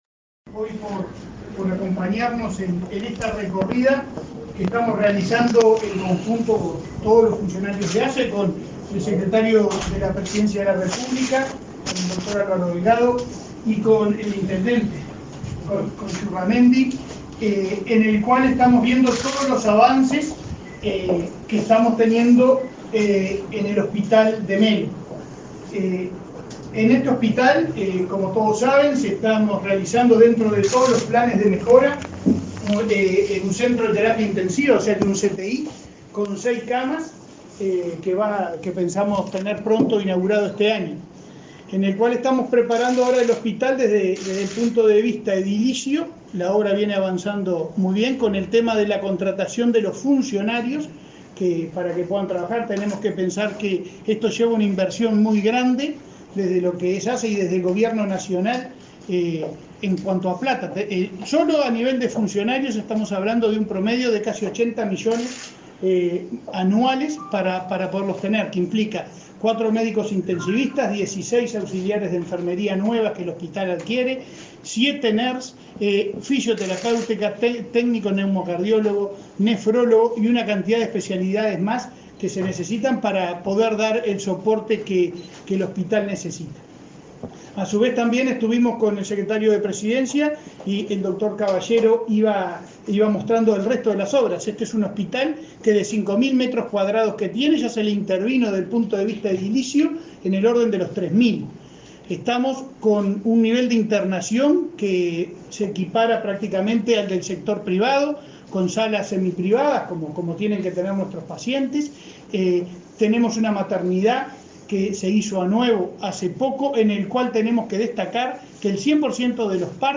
Conferencia del presidente de ASSE y el secretario de Presidencia en hospital de Melo
El presidente de ASSE, Leonardo Cipriani, y el secretario de Presidencia, Álvaro Delgado, brindaron una conferencia de prensa en el hospital de Melo,